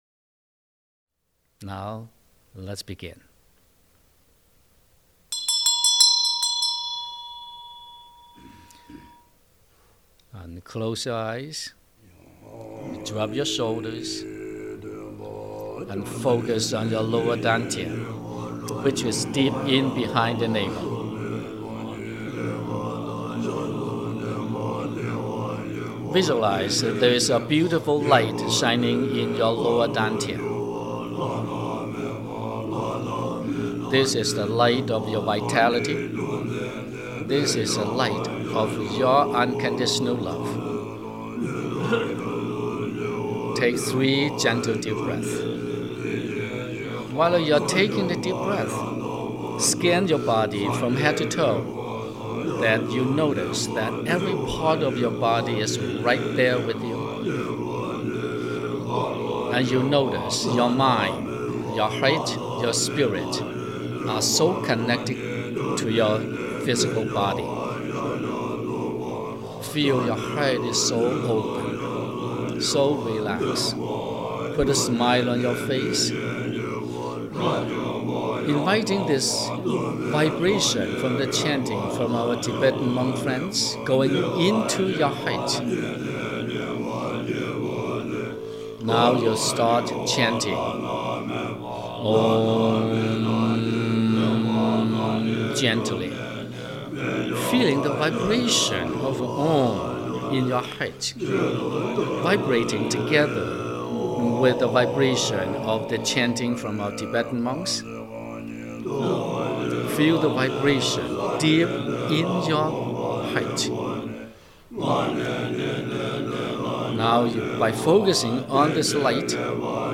7 Guided Meditations | Stream Online or Download